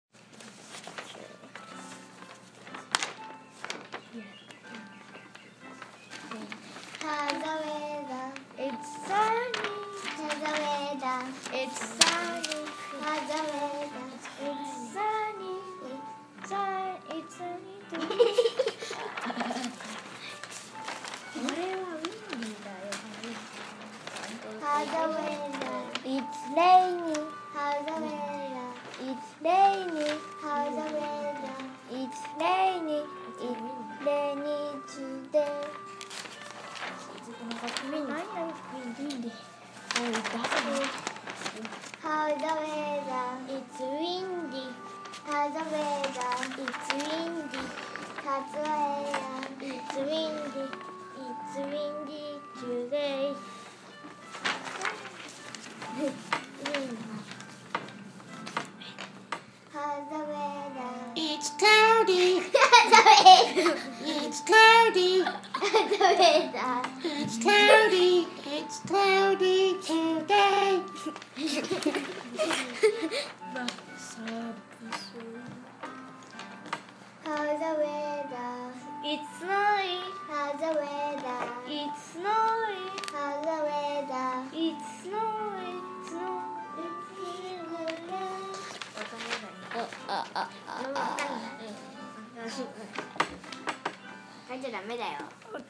Magic Time, Tuesday, weather, chant